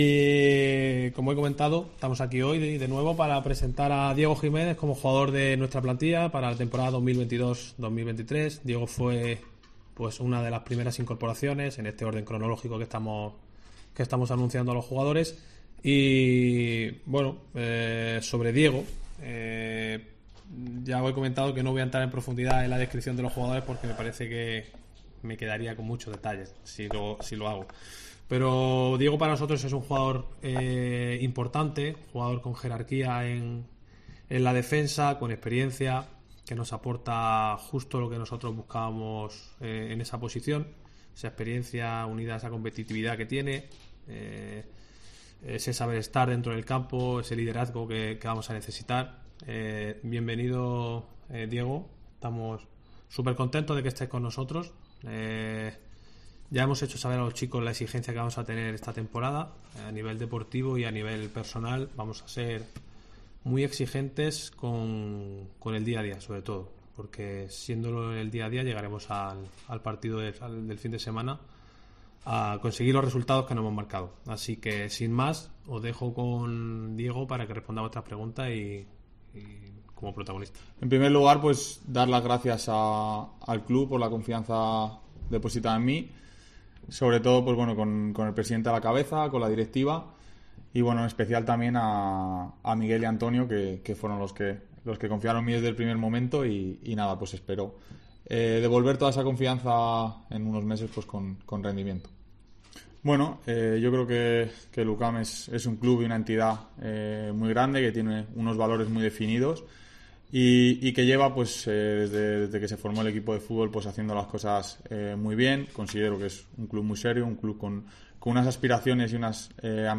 ha sido presentado esta mañana en la sala de prensa del BeSoccer La Condomina